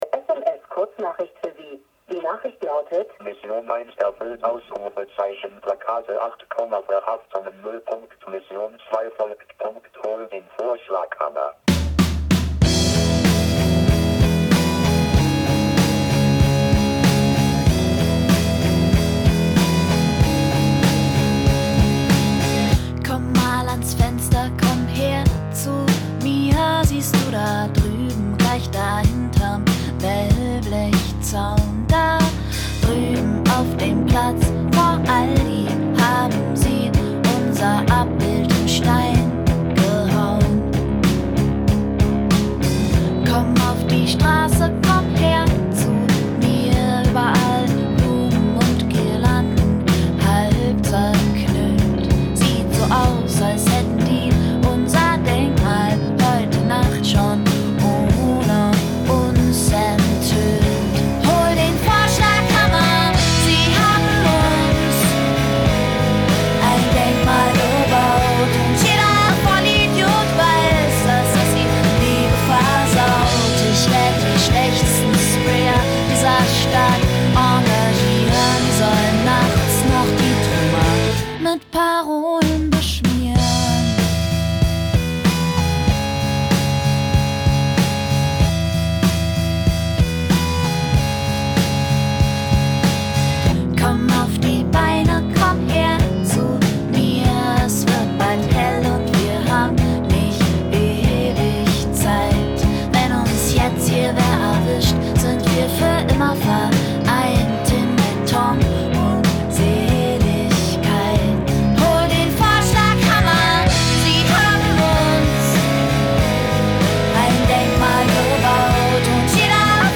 Pop GER